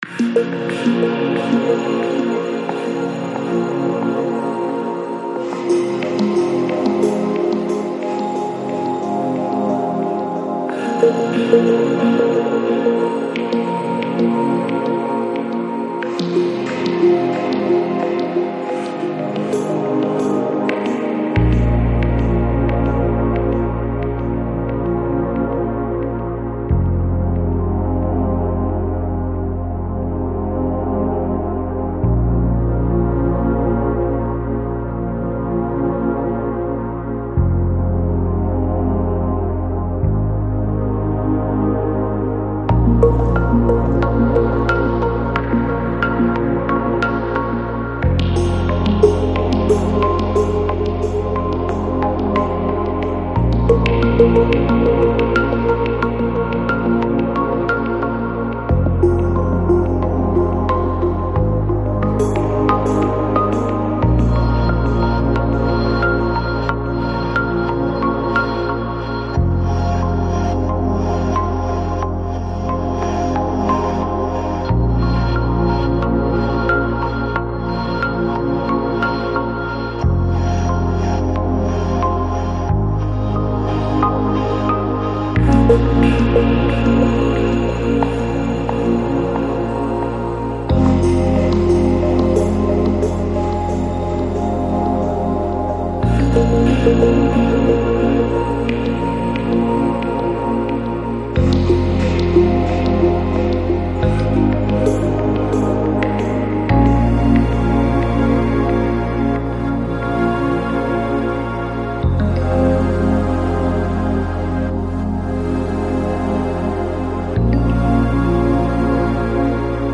Жанр: Lounge, Chill Out, Downtempo, Balearic